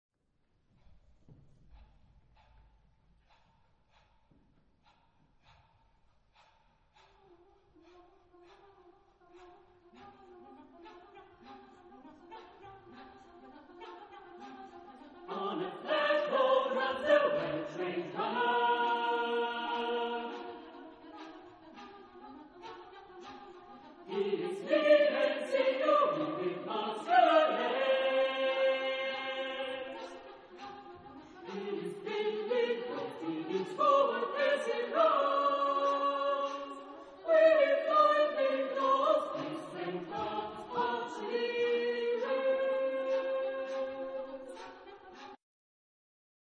Type de matériel : Partition choeur seul
Type de choeur : SAATB  (5 voix mixtes )